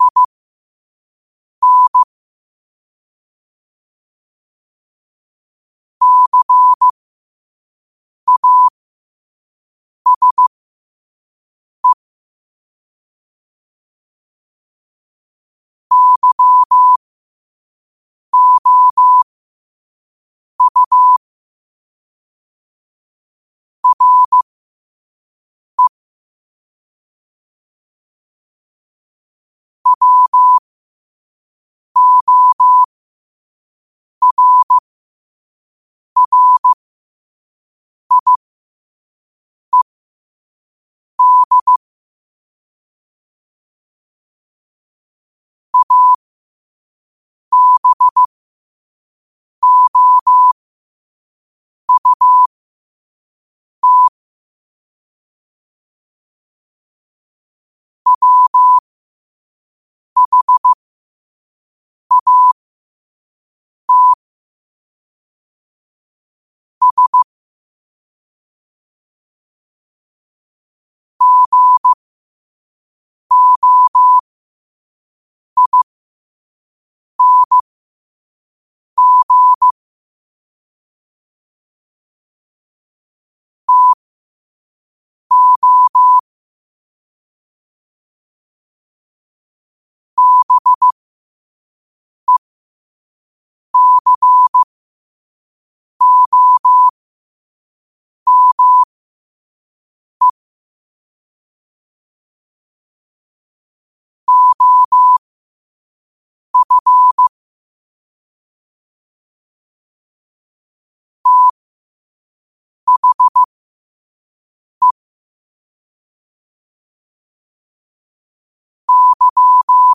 New quotes every day in morse code at 5 Words per minute.